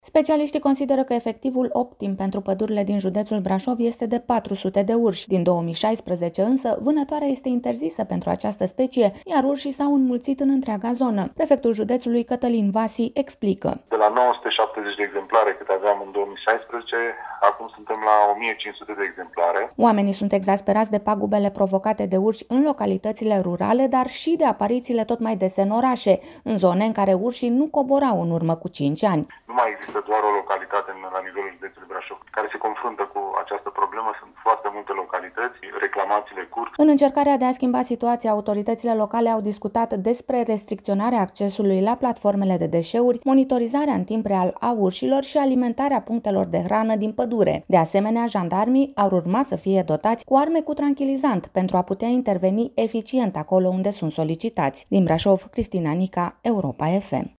Prefectul judetului, Cătălin Văsîi, explică:
La rândul său, primarul Brașovului, Allen Coliban, spune ce se poate face pentru a opri urșii să vină în oraș: